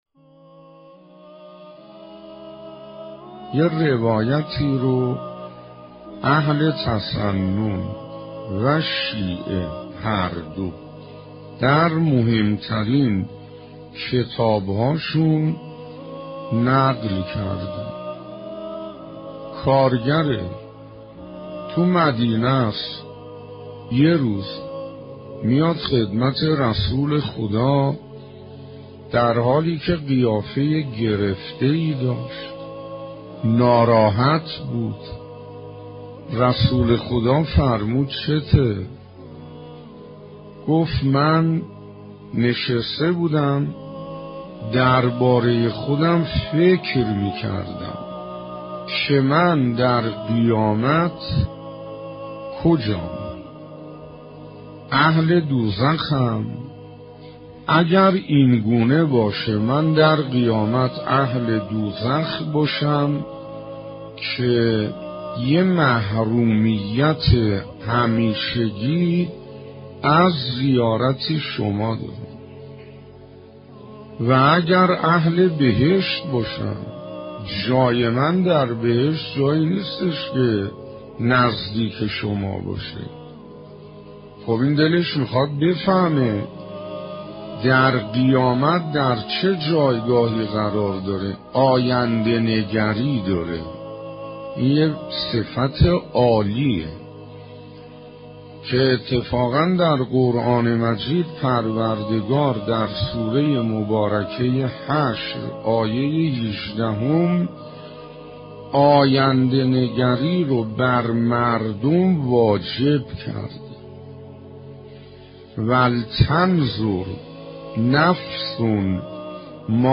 کسانی که از خدا و پیامبر اطاعت کنند، در زمره کسانی از پیامبران و صدیقان و شهیدان و شایستگان خواهند بود که خدا به آنان نعمت داده؛ و اینان نیکو رفیقانی هستند.«آیه ۶۹ سوره "نساِء». در ادامه پادکست با عنوان «سرانجام اطاعت از خدا و رسول» با سخنرانی شیخ حسین انصاریان تقدیم مخاطبان گرامی ایکنا می‌شود.